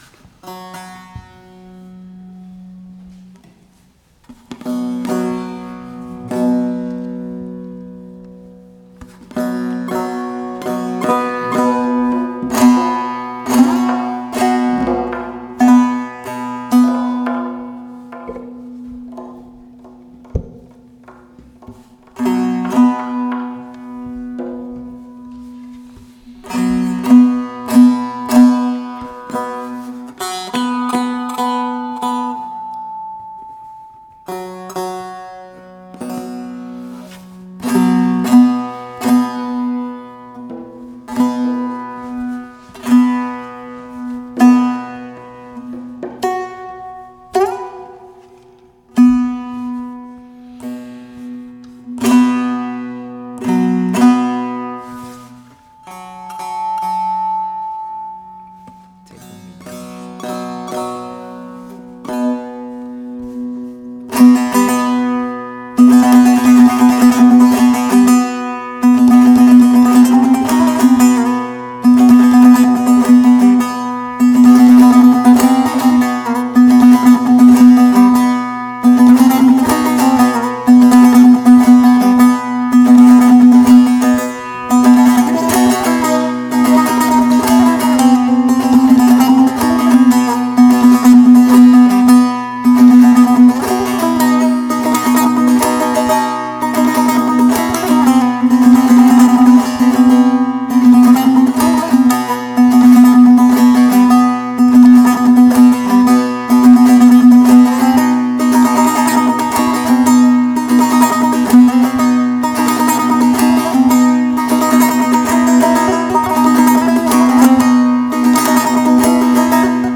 tanbur
tombak, daf
World Music